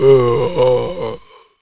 c_zombim5_atk2.wav